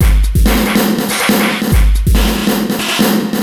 E Kit 06.wav